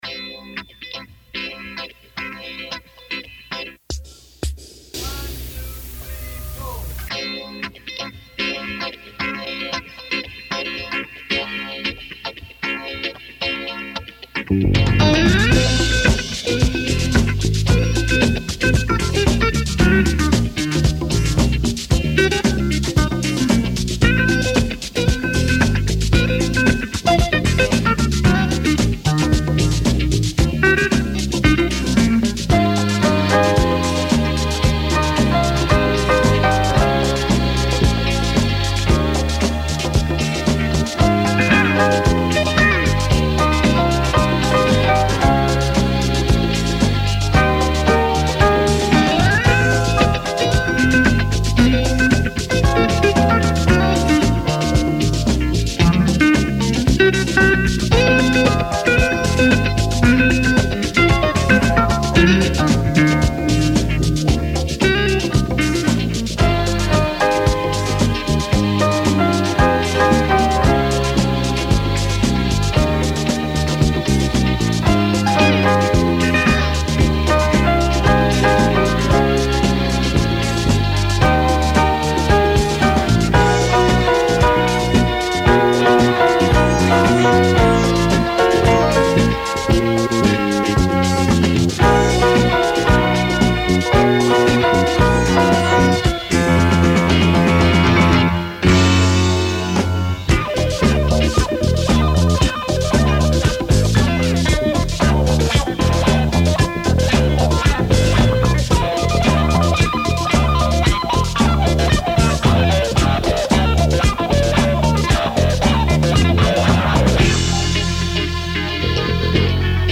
I still have a cassette tape of the song they were working on at the time.